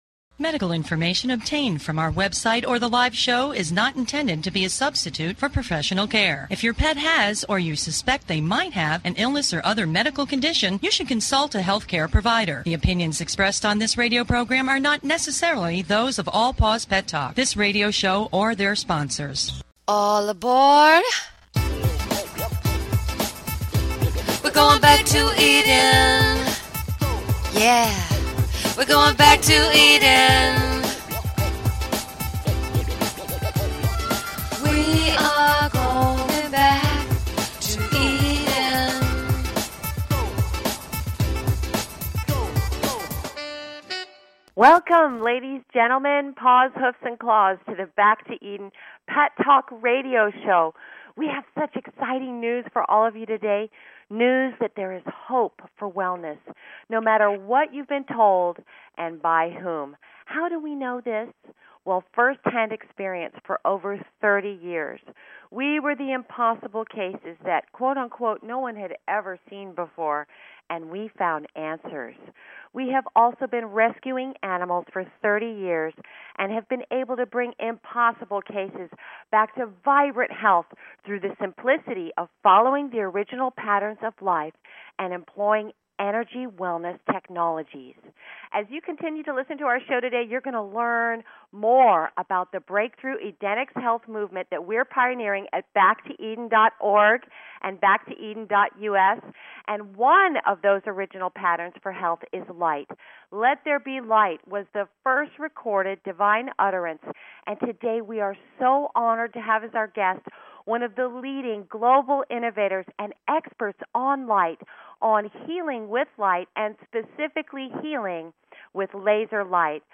Back 2 Eden Debut Show on BBS Radio